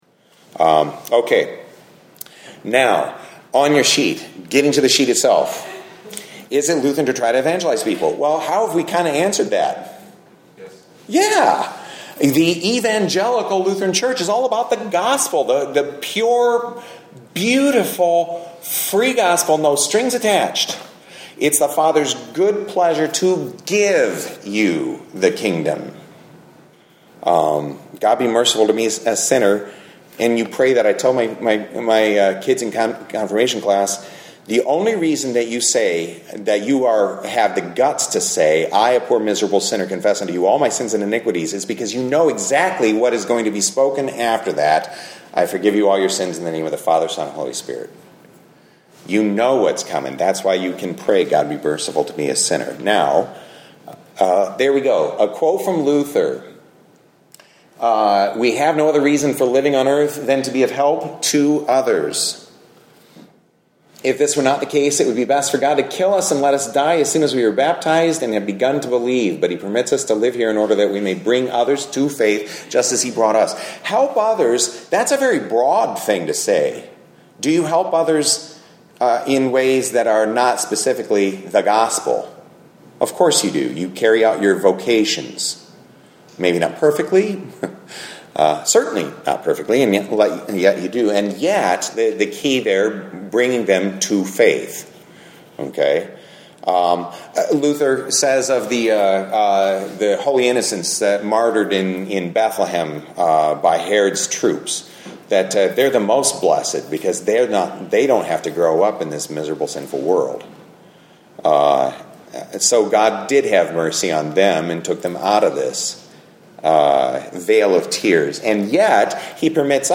Grace Lutheran (LCMS) hosted the Building Bridges Seminar on Saturday, August 10th at 9 am.